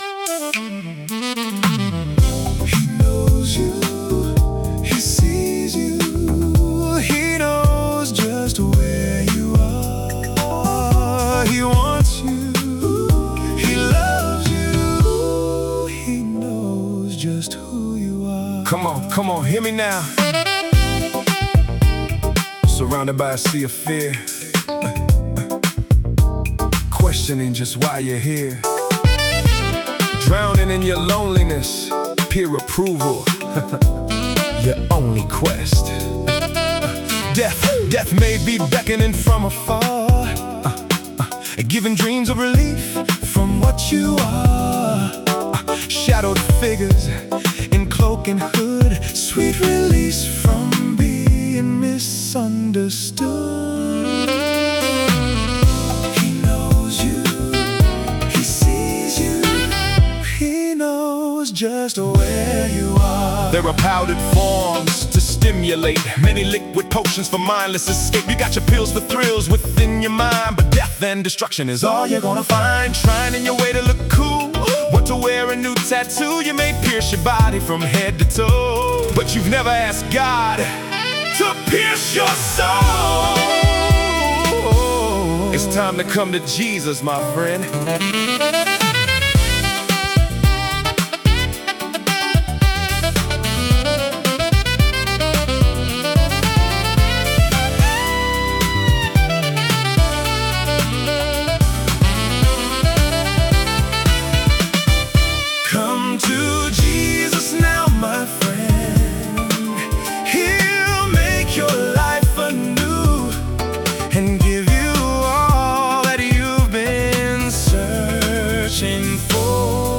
After coming to Thailand in 1999, I began to do some recording in my spare time at a local Thai studio.
I really hope you enjoy this rendition of a late 1990’s hybrid rap song.